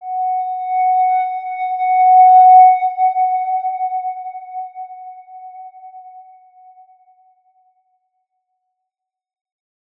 X_Windwistle-F#4-pp.wav